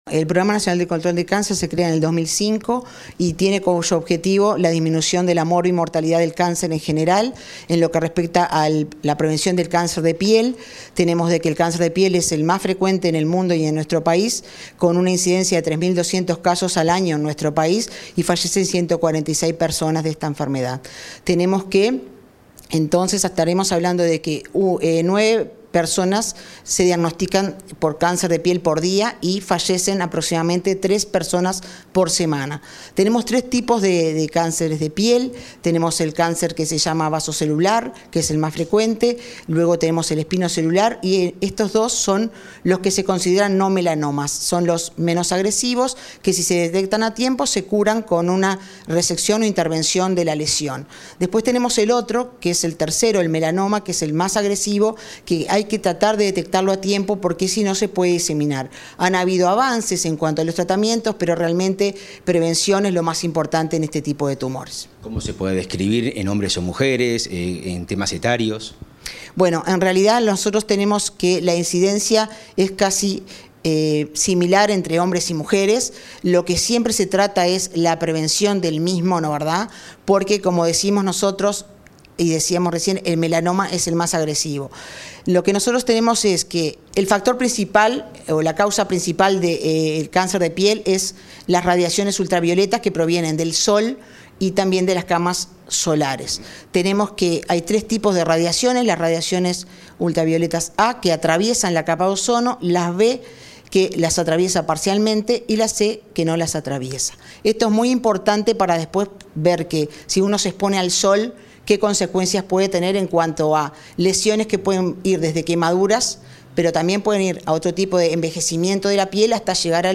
Entrevista a Marisa Fazzino, directora del Programa Nacional de Control del Cáncer